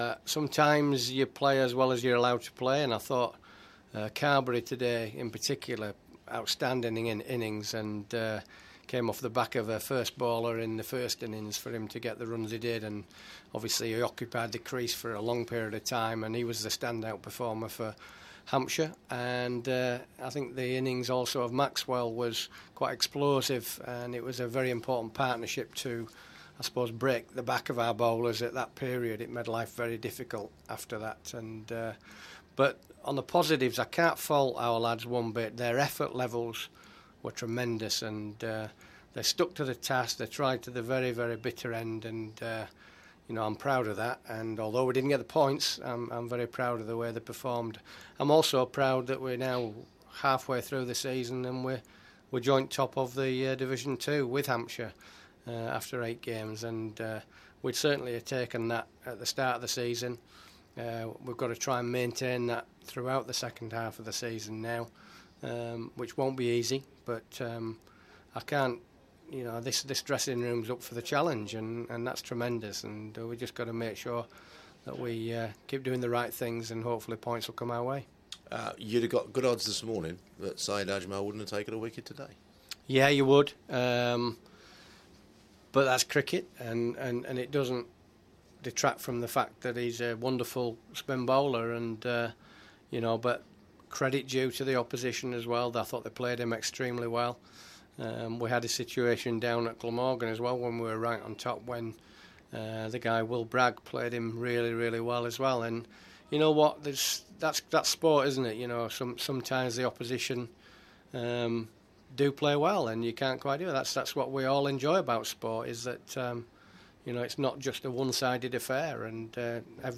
Worcestershire director of cricket Steve Rhodes talks to BBC Hereford and Worcester after the draw against Hampshire at New Road.